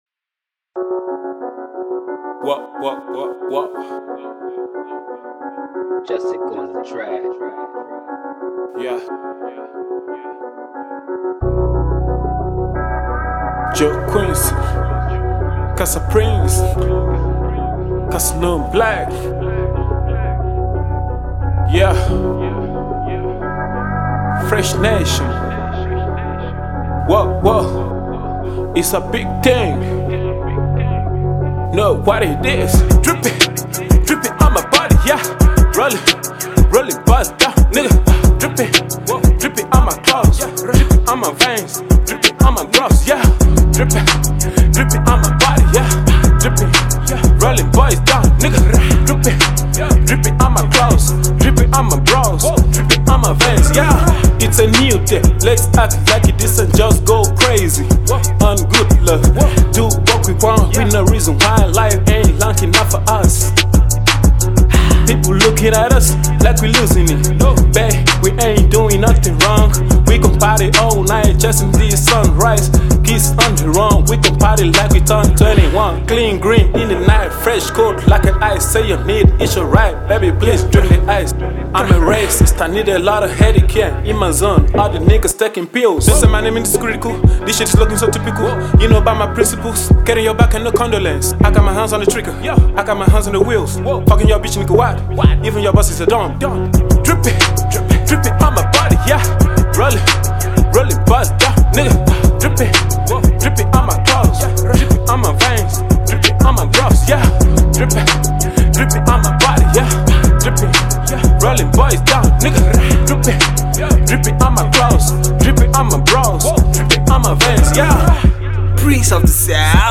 Fast emerging trap star